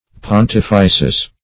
Pontifices - definition of Pontifices - synonyms, pronunciation, spelling from Free Dictionary
pontifices.mp3